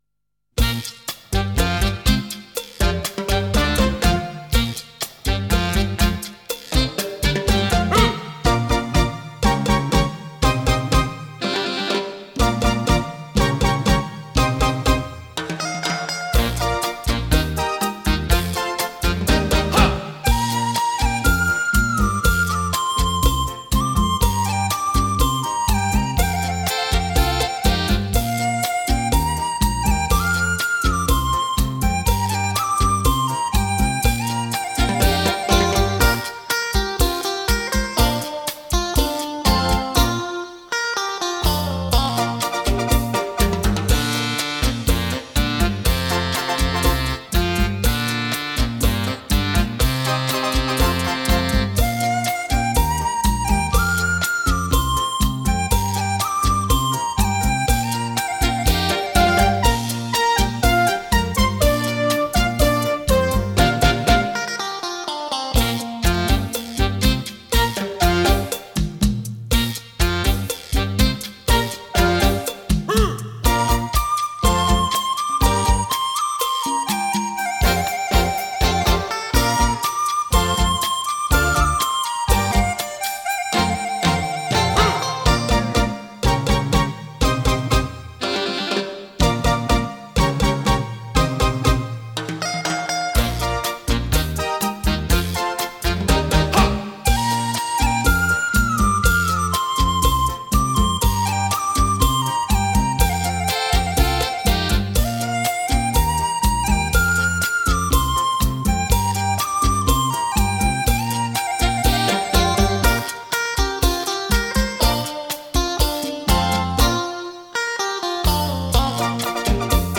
第一段联奏
是六段联奏